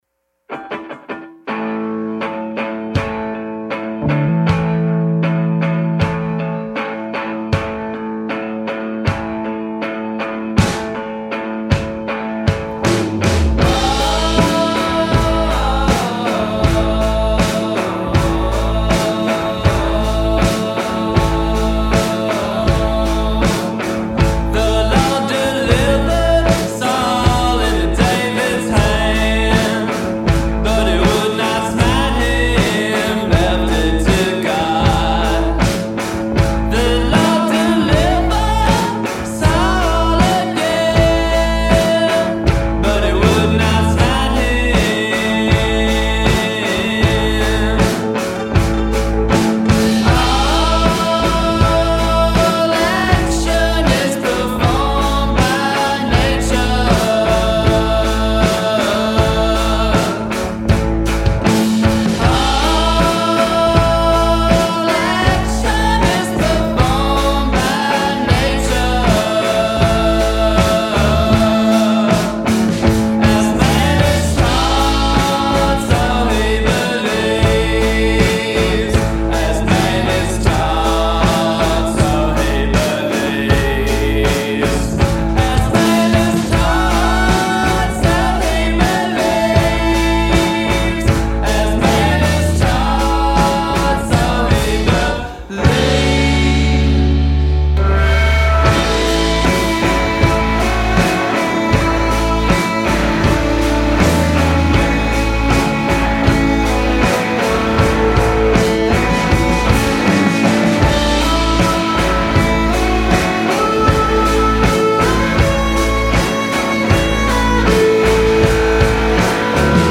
five-piece indie band